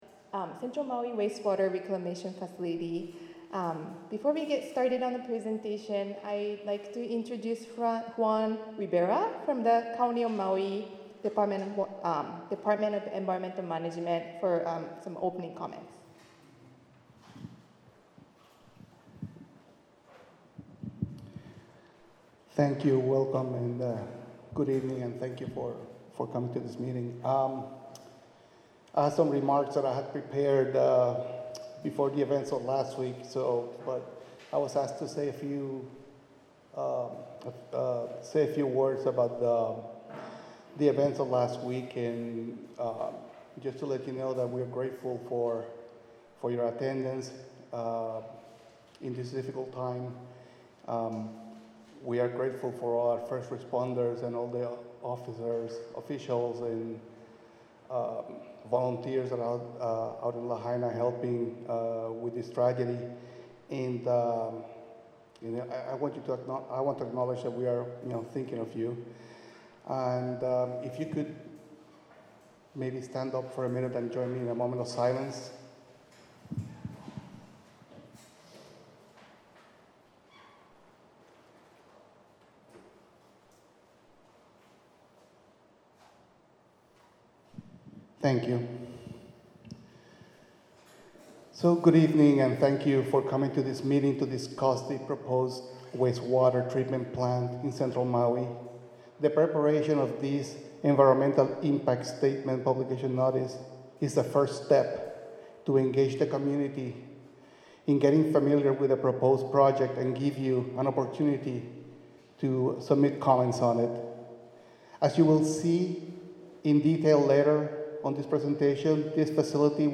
2026-03-23-MA-DEIS-Hoonani-Village-Mixed-Use-Development-Scoping-Mtg-Audio.mp3